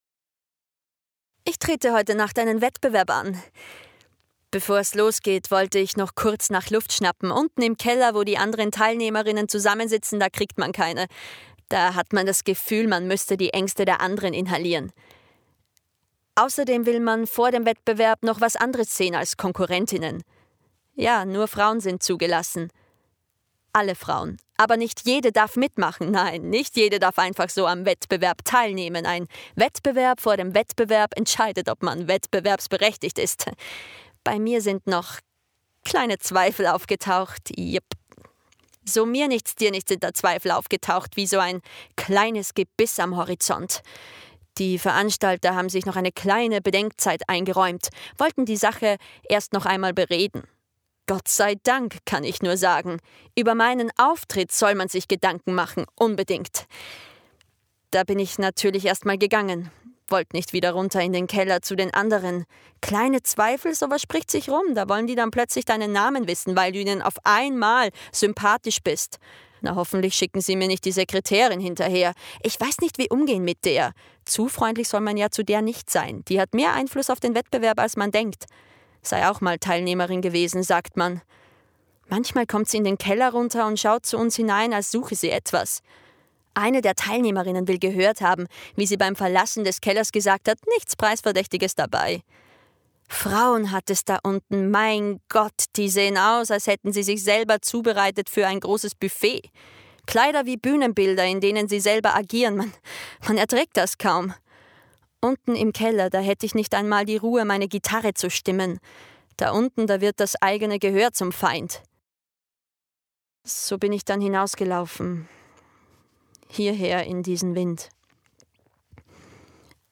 Hörspiel Kleine Zweifel
Hörspiel_Kleine-Zweifel.mp3